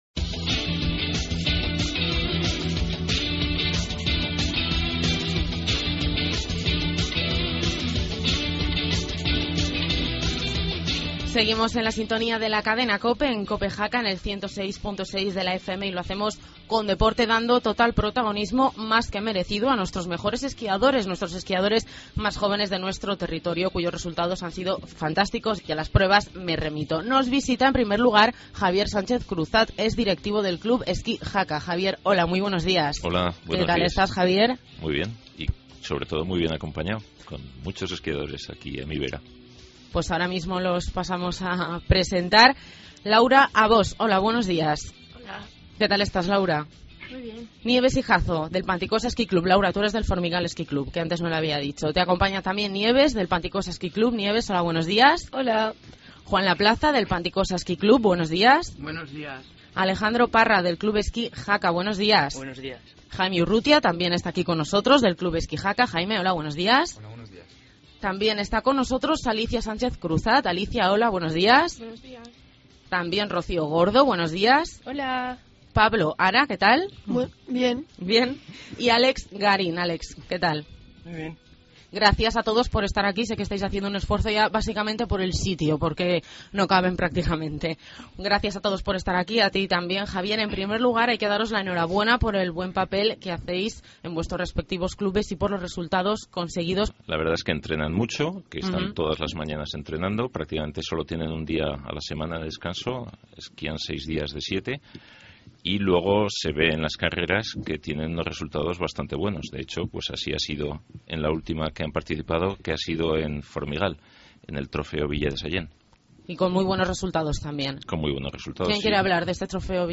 charla con los esquiadores del Club Esquí Jaca, Panticosa Esquí Club y Formigal Esquí Club sobre los útimos resultados deportivos.